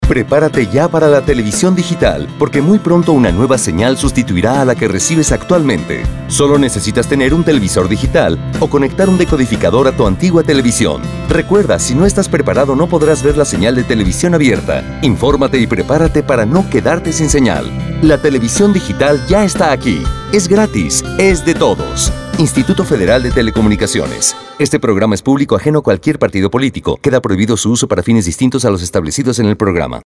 Spot de radio: Escuchar